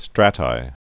(strătī, strātī)